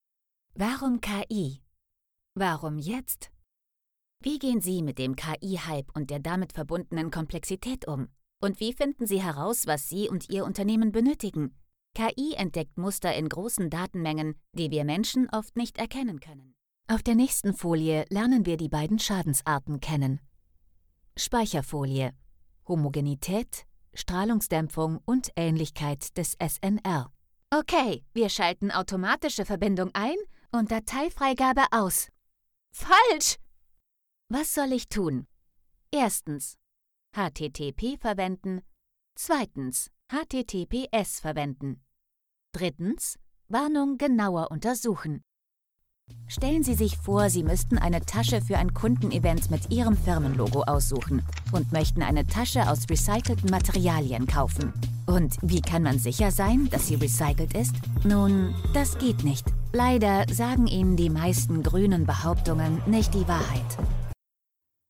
Chaude, Corporative, Commerciale, Naturelle, Amicale
E-learning